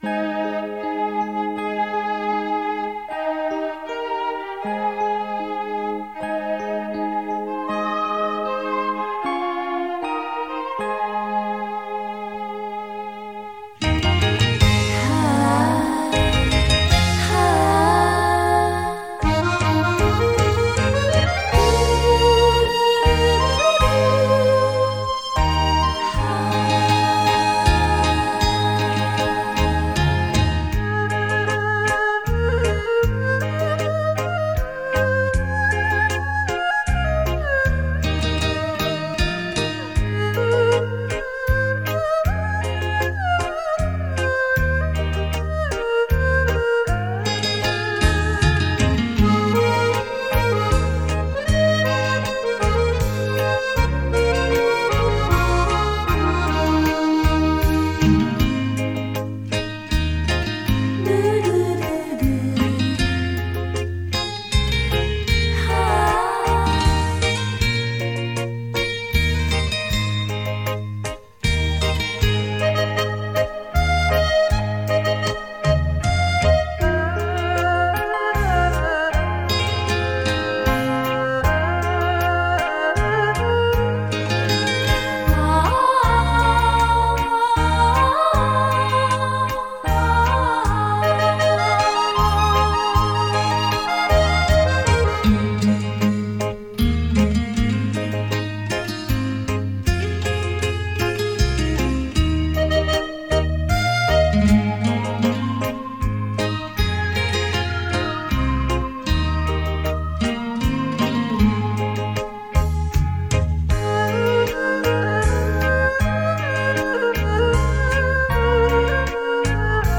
身历其境的临场效果